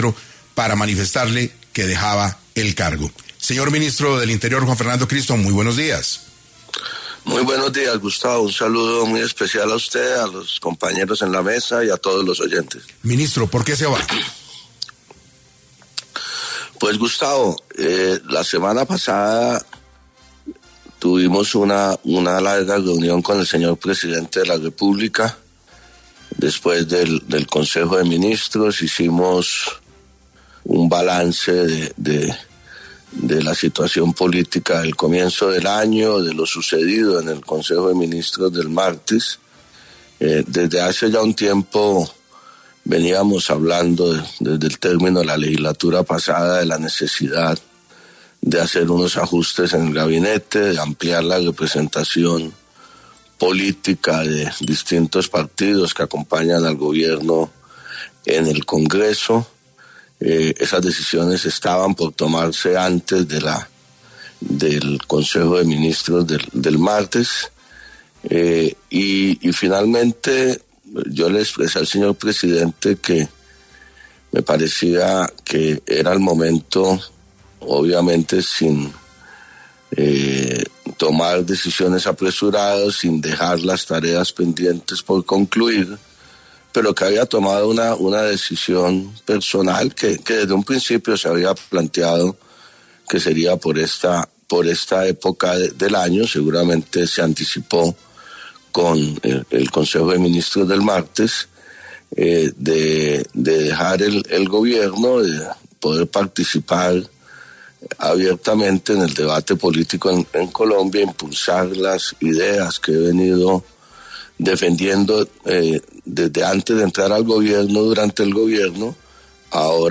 Juan Fernando Cristo renunció al Ministerio del Interior del gobierno de Gustavo Petro durante la entrevista con 6AM.